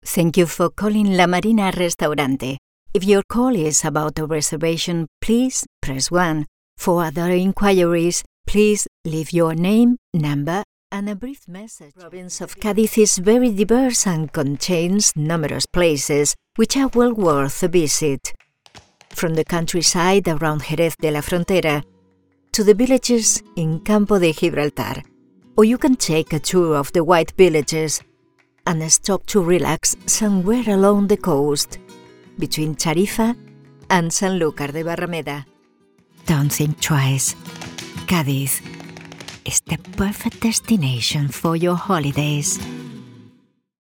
Female
Bright, Character, Children, Natural, Warm, Versatile, Confident
Andalucía (native)
People say my voice sounds very clear, warm, trustworthy and expressive and that it can also sound fun, educational and informative…
Microphone: Neumann TLM 103, Rode NT1A & Blue Microphones Yeti USB